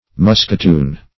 Search Result for " musketoon" : The Collaborative International Dictionary of English v.0.48: Musketoon \Mus`ket*oon"\, n. [F. mousqueton; cf. It. moschettone.] 1. A short musket.